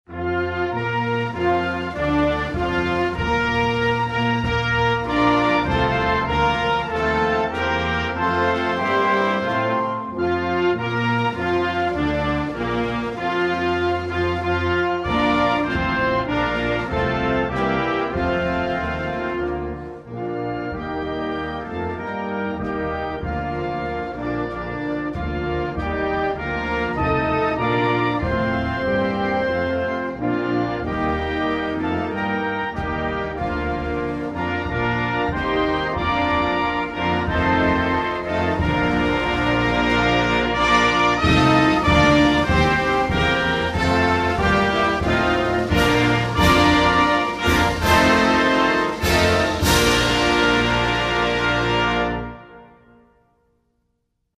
Na spletu poišči himne Avstralije, Nove Zelandije in Francoske Polinezije.